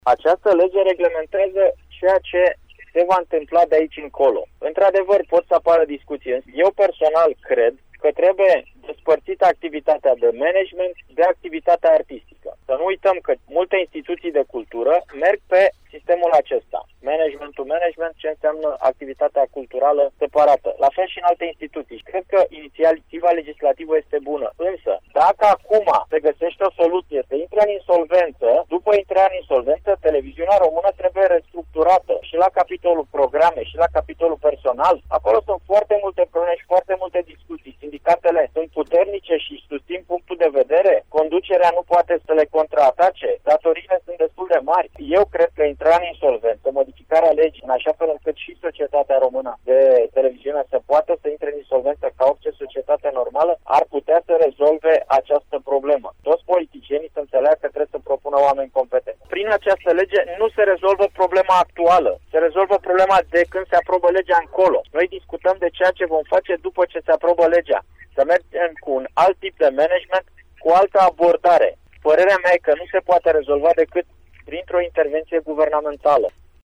Departajarea activității de management de cea artistică, intrarea în insolvență și restructurarea personalului pot rezolva deficiențele serviciului public de televiziune, a afirmat deputatul PSD Romeo Rădulescu, în cadrul dezbaterii de la Radio România Oltenia Craiova: